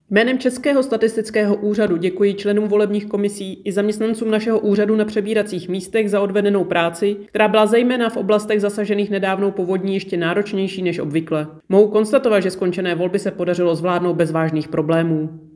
Vyjádření Evy Krumpové, 1. místopředsedkyně Českého statistického úřadu, soubor ve formátu MP3, 507.23 kB